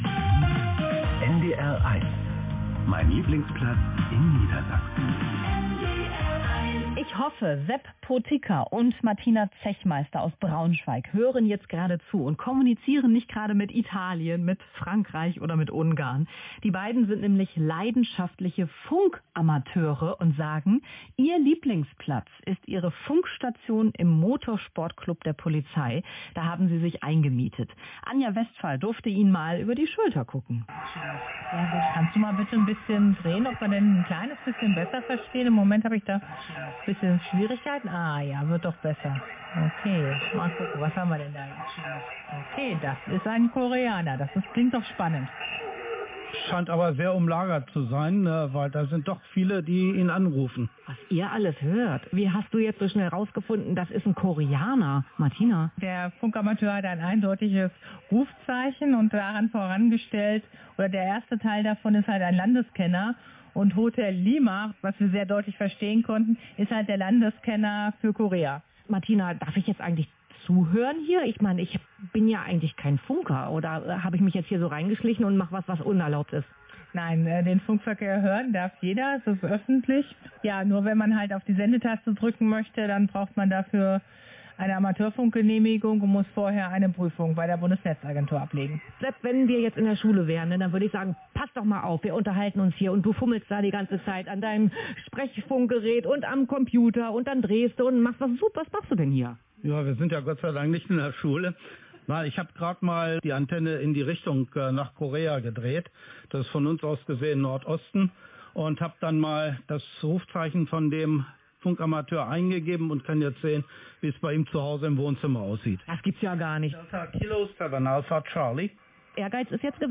Am 1. Juli 2023 wurde im Rahmen der NDR-Reihe “Mein Lieblingsplatz” ein Beitrag aus dem Amateurfunkzentrum in Braunschweig im Rundfunk bei